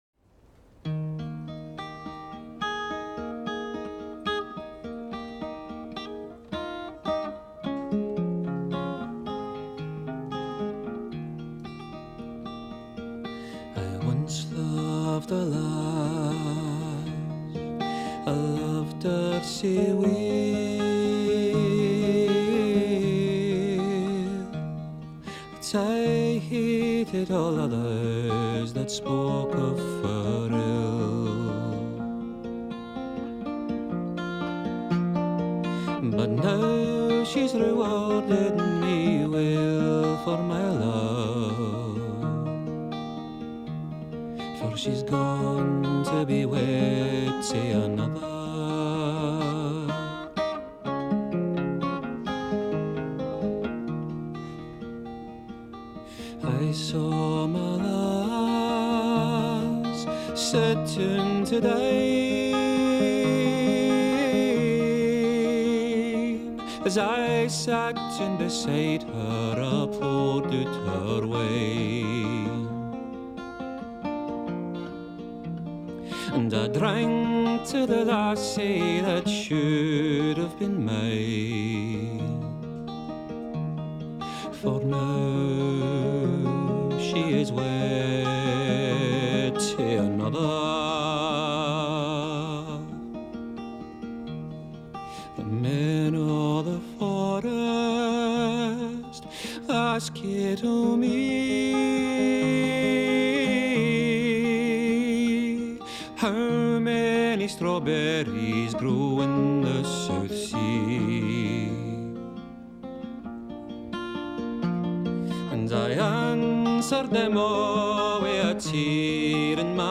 chanson folklorique britannique